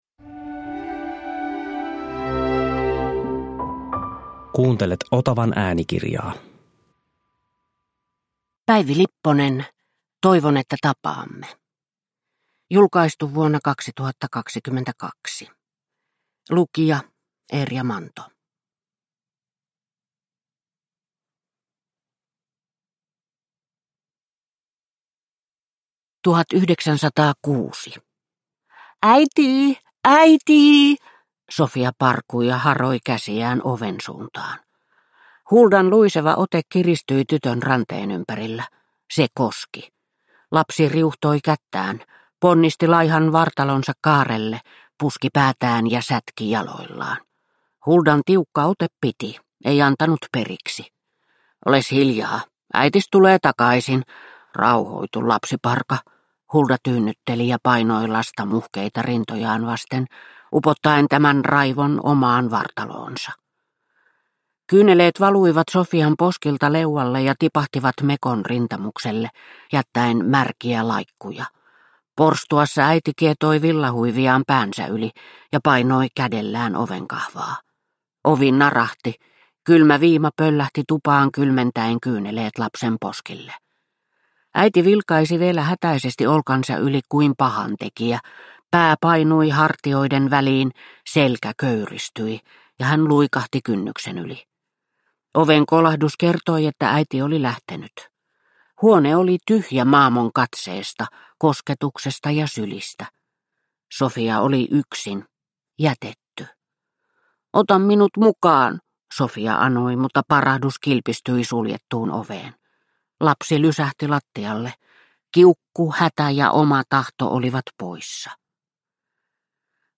Toivon että tapaamme – Ljudbok – Laddas ner